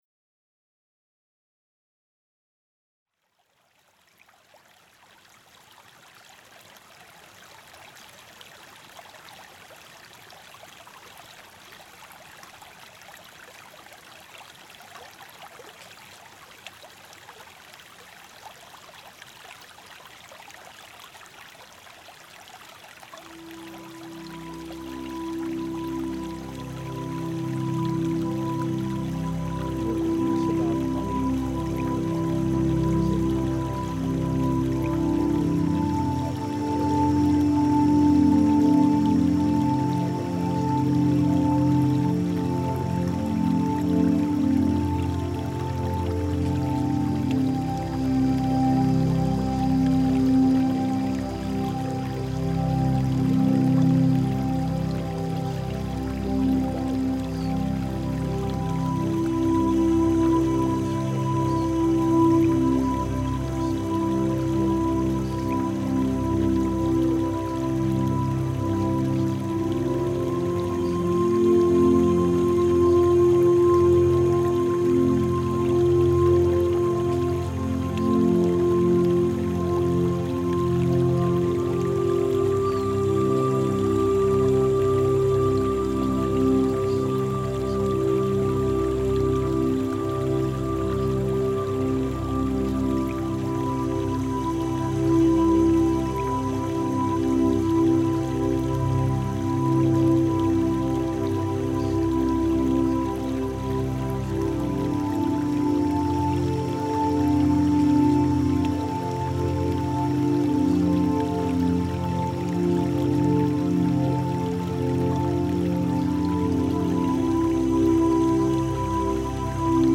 INSTRUCTIONS Subliminal Activation sessions embed the mental programming script inside subliminal technology. This script is hidden behind relaxing music.
You may hear fragments of words from time to time.
SUBLIMINAL+-++AM+Module+5+-+Money+Likes+Freedom.mp3